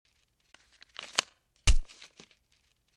port inject sound
Inject.ogg